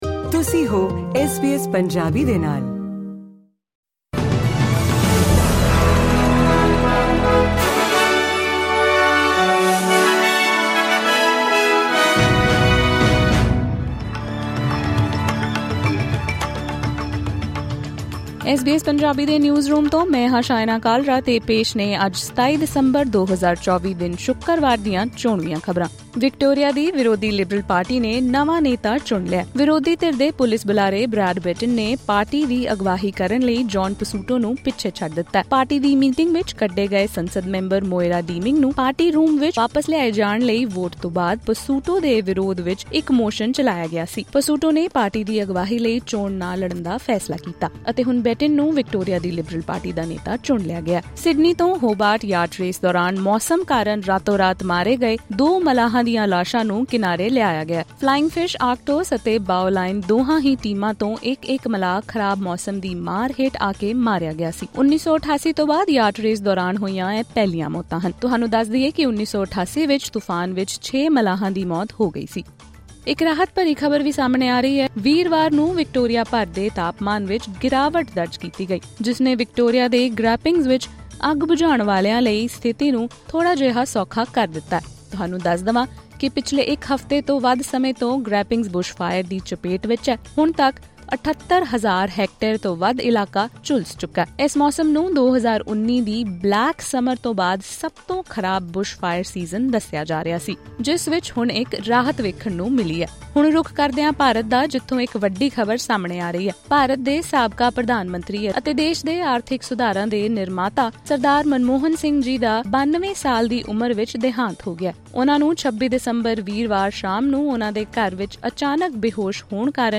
ਖ਼ਬਰਨਾਮਾ: ਬ੍ਰੈਡ ਬੈਟਿਨ ਸੰਭਾਲਣਗੇ ਵਿਕਟੋਰੀਆ ਦੀ ਵਿਰੋਧੀ ਲਿਬਰਲ ਪਾਰਟੀ ਦੀ ਕਮਾਨ